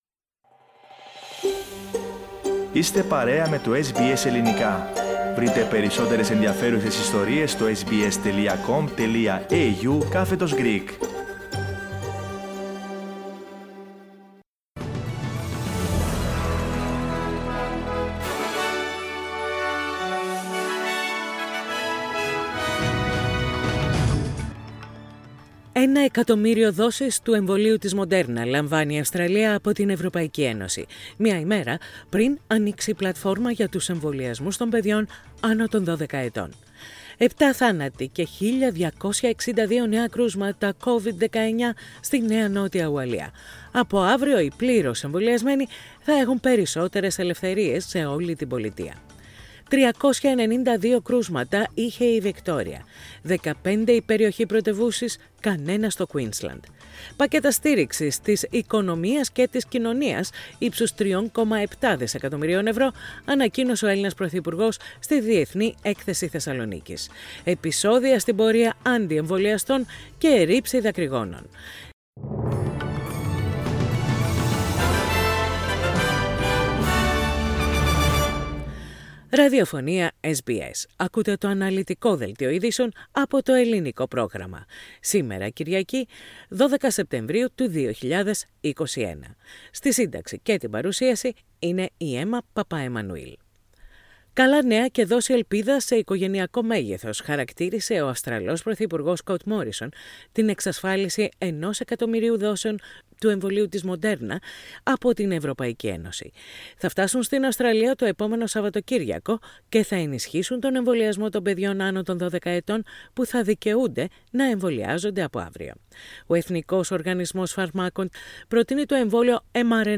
Ειδήσεις στα Ελληνικά - Κυριακή 12.9.21
Οι κυριότερες ειδήσεις της ημέρας από το Ελληνικό πρόγραμμα της ραδιοφωνίας SBS.